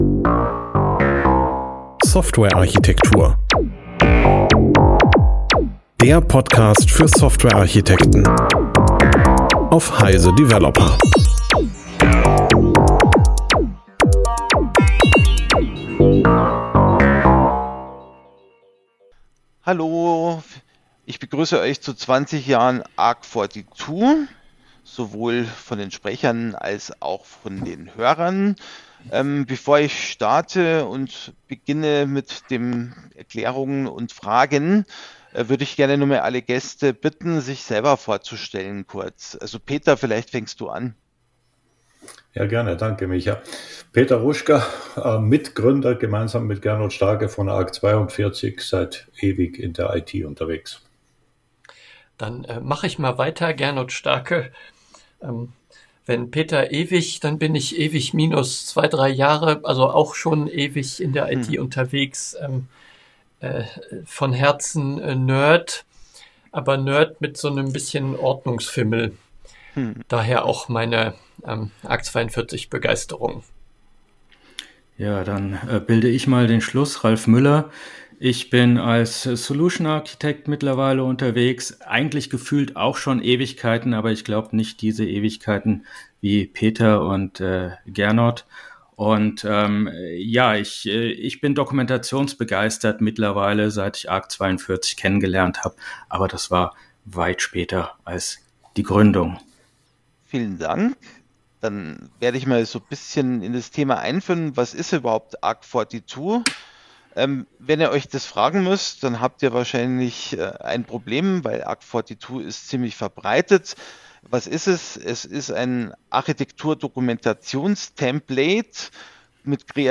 zum Gespräch. arc42 hat sich im Lauf der Jahre zum bekannteste Ansatz entwickelt, Softwarearchitektur systematisch zu kommunizieren und dokumentieren.